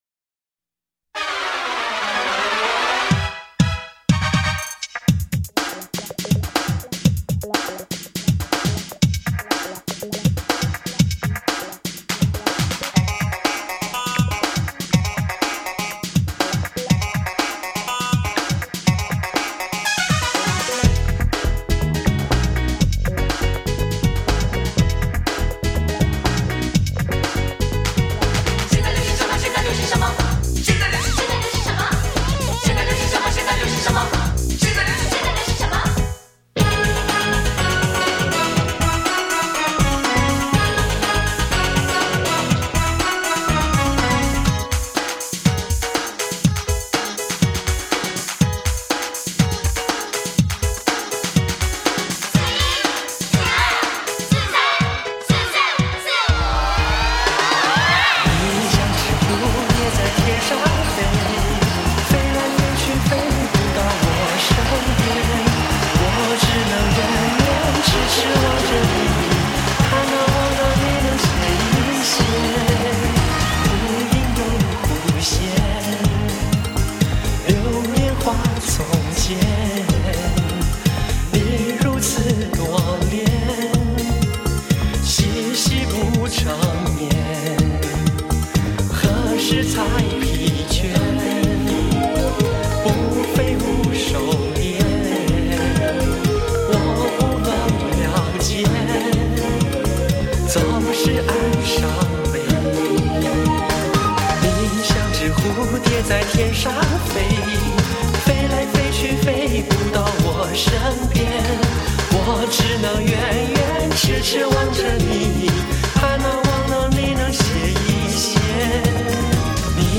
港台流行金曲大联唱
45就是 采45转快转的方式演唱串联当红歌曲的组曲 记录着70.80年代台湾流行乐史